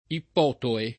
Ippotoe [ ipp 0 toe ]